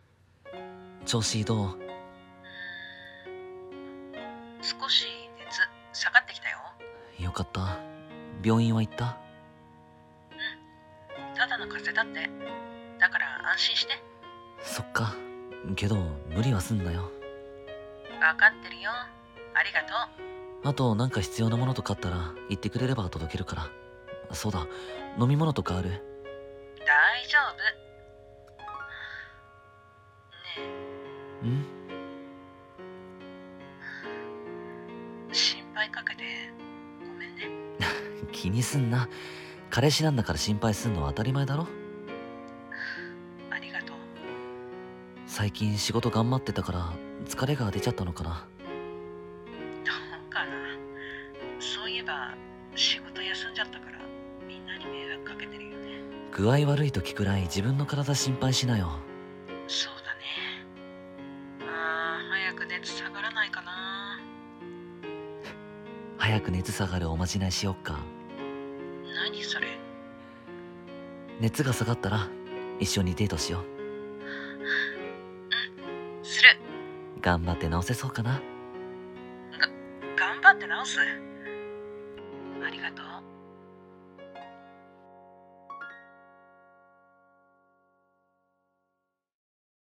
風邪引きの君へ】※恋愛コラボ声劇